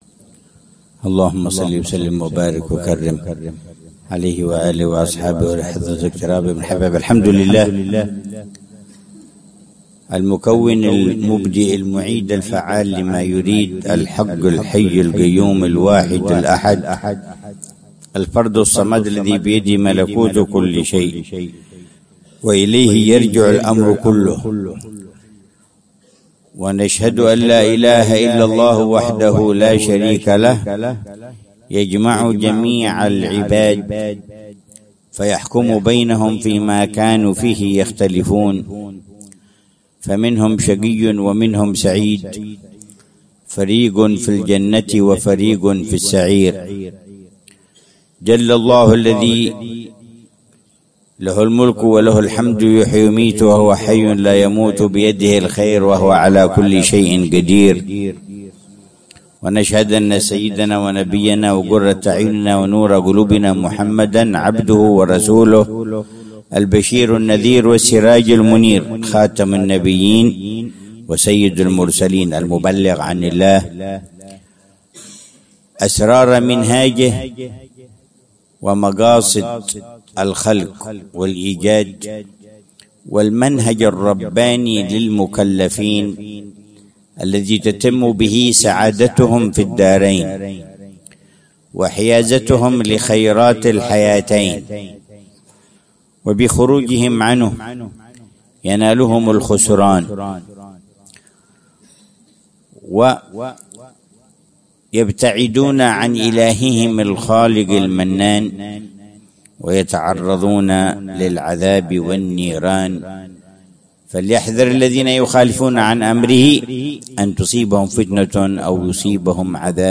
محاضرة العلامة الحبيب عمر بن محمد بن حفيظ في المولد السنوي الذي يقيمه طلاب مدرسة دار المصطفى الأهلية بتريم ضحى الخميس 19 ربيع الأول 1447هـ بعنوان: